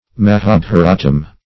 Mahabarata \Ma*ha*ba"ra*ta\, Mahabharatam \Ma*ha*bha"ra*tam\, n.